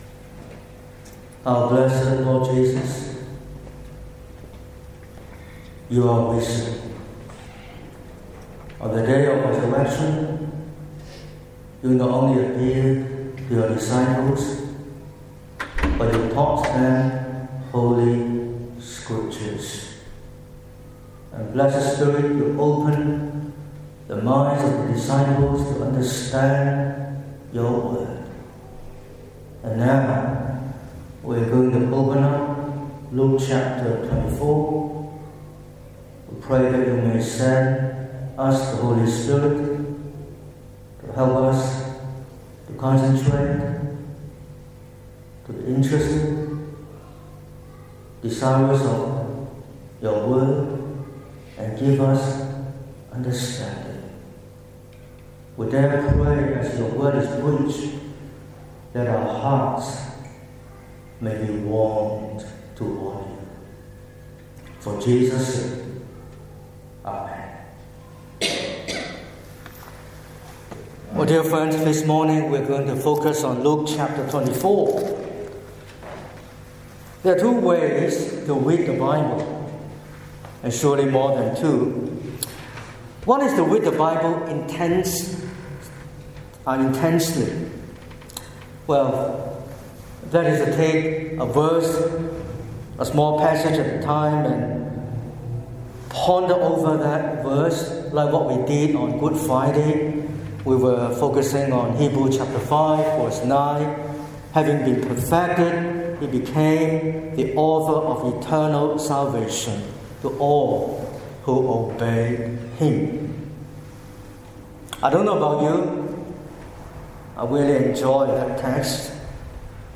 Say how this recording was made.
05/04/2026 – Easter Sunday Morning Service: Luke 24 – The Resurrection of Christ